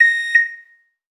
pgs/Assets/Audio/Alarms_Beeps_Siren/beep_04.wav at master
beep_04.wav